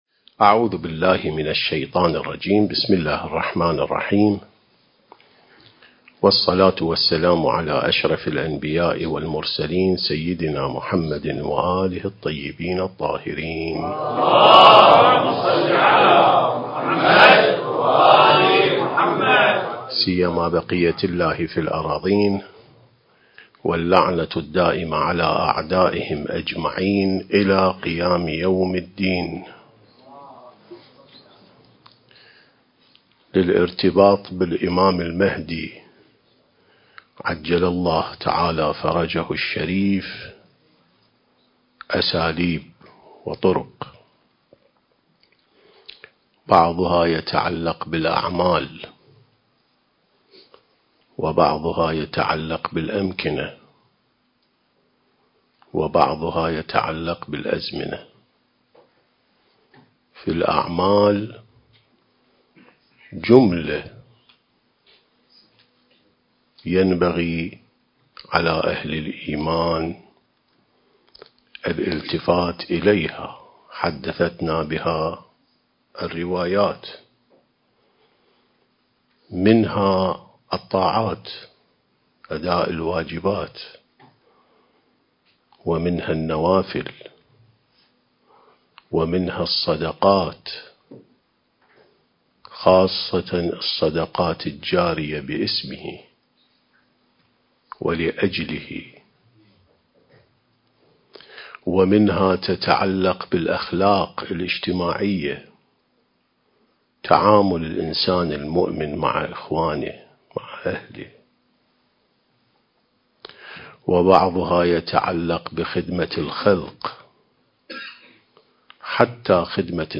عين السماء ونهج الأنبياء سلسلة محاضرات: الارتباط بالإمام المهدي (عجّل الله فرجه)/ (5)